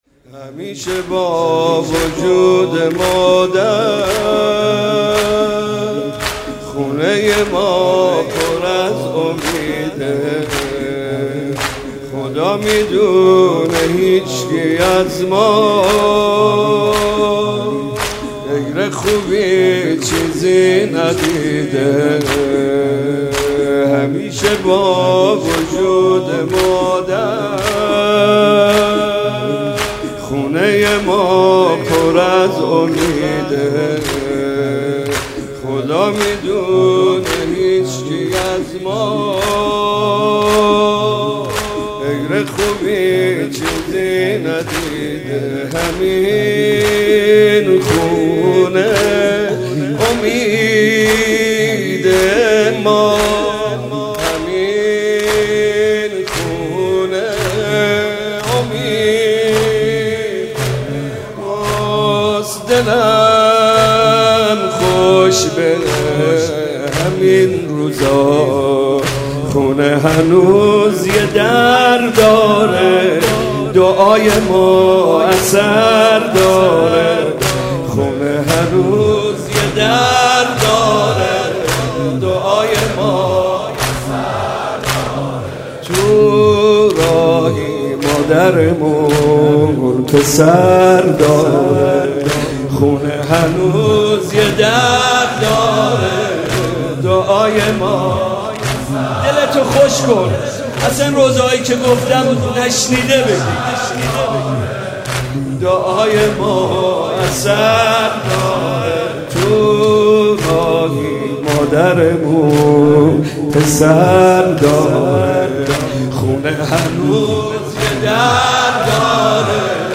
مداحی و نوحه
غیر از خوبی چیزی ندیده» [فاطمیه دوم] [زمینه]